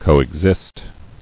[co·ex·ist]